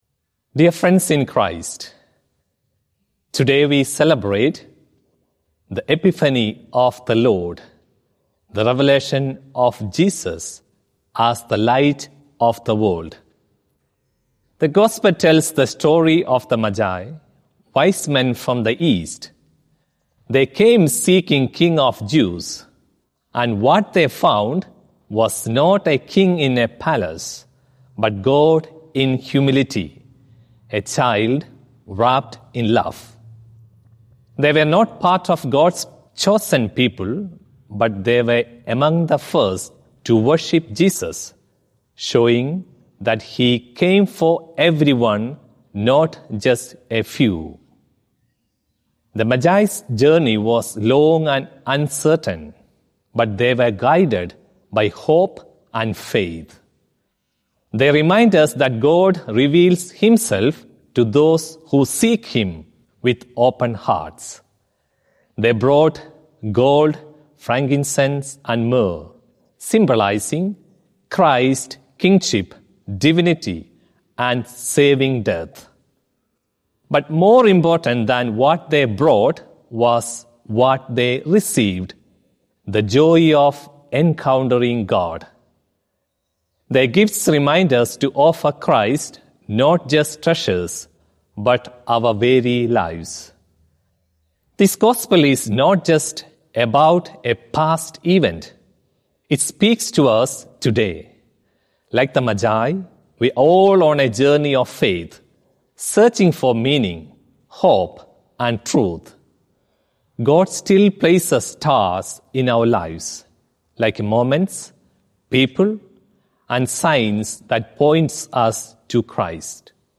Archdiocese of Brisbane Feast of the Epiphany of the Lord - Two-Minute Homily